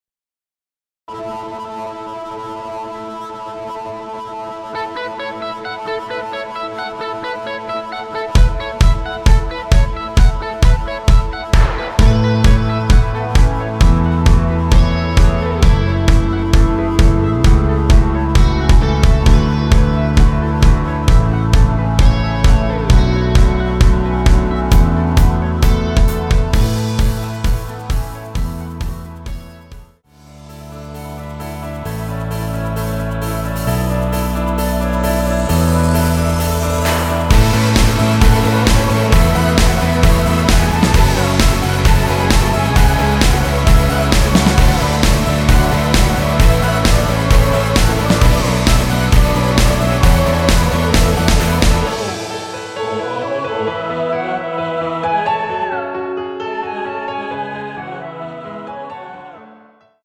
원키 멜로디 포함된 MR입니다.(미리듣기 확인)
앞부분30초, 뒷부분30초씩 편집해서 올려 드리고 있습니다.
중간에 음이 끈어지고 다시 나오는 이유는